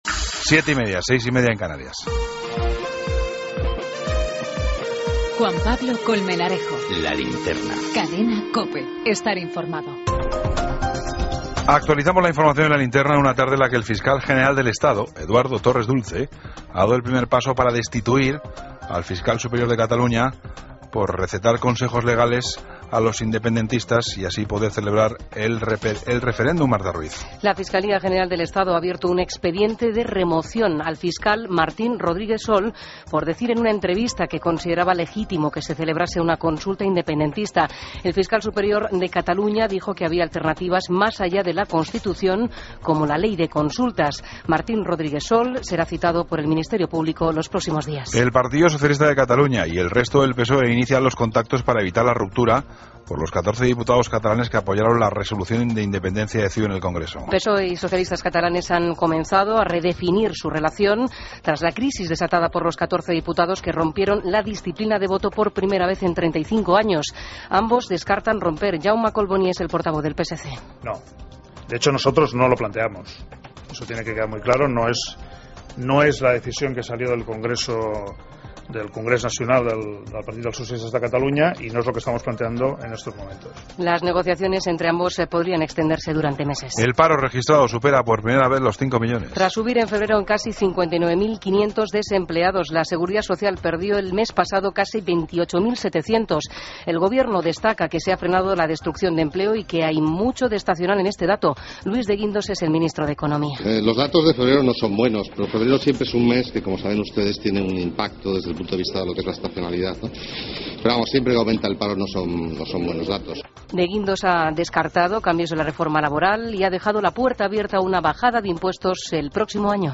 Ronda de corresponsales.
Entrevista